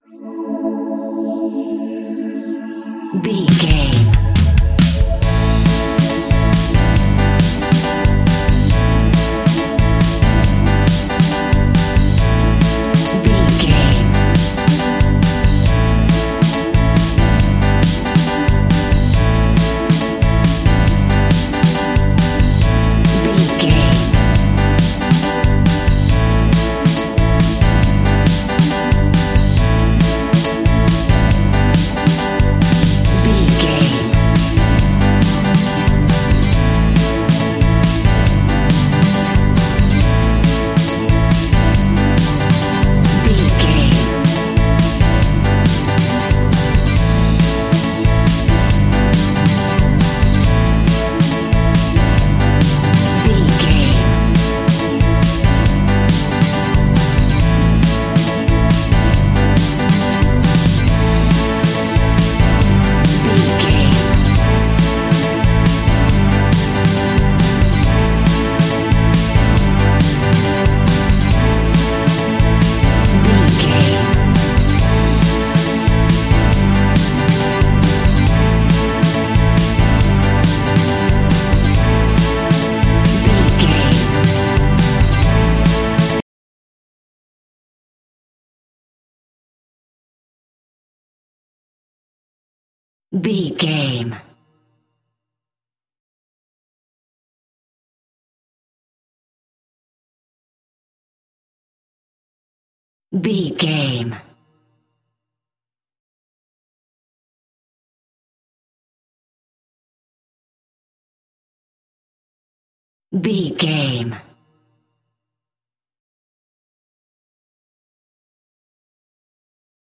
Ionian/Major
pop rock
indie pop
energetic
uplifting
catchy
upbeat
acoustic guitar
electric guitar
drums
piano
organ
bass guitar